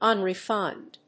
音節un・re・fined 発音記号・読み方
/`ʌnrɪfάɪnd(米国英語), ˌʌnri:ˈfaɪnd(英国英語)/